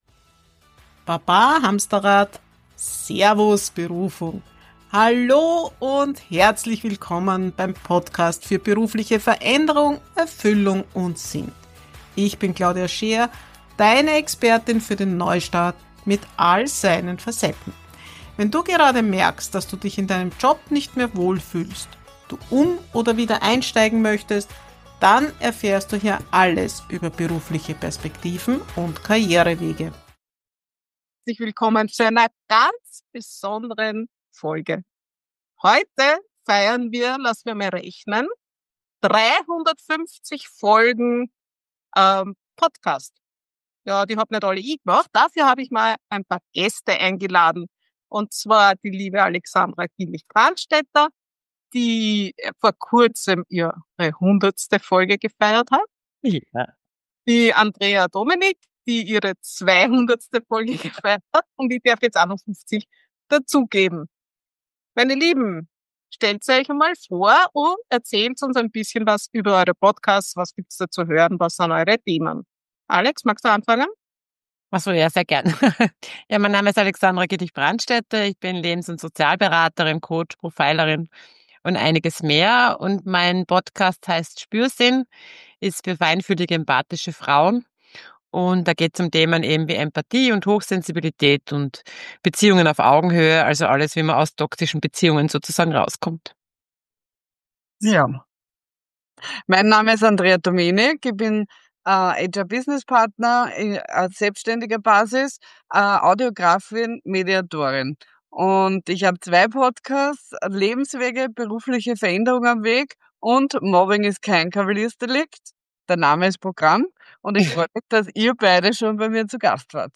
Drei Frauen.